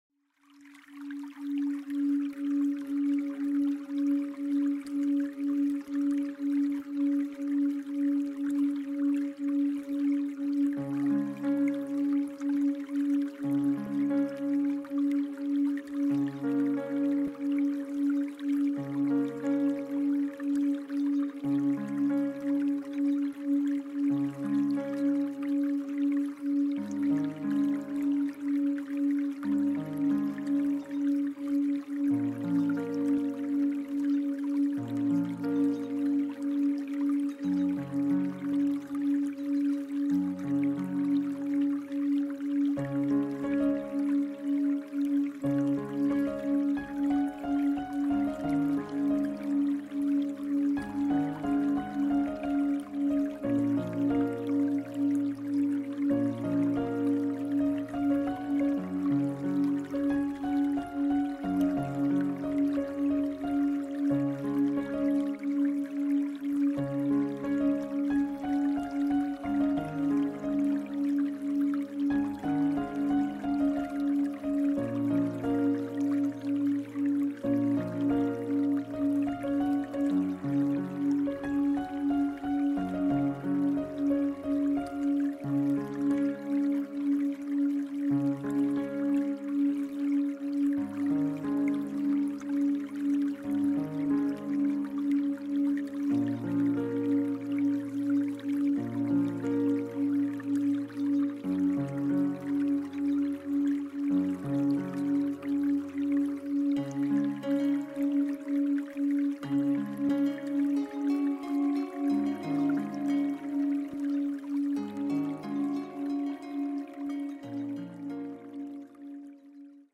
Frecuencia Binaural Delta para la sound effects free download
Nuestra frecuencia está creada a partir de la frecuencia 285 Hz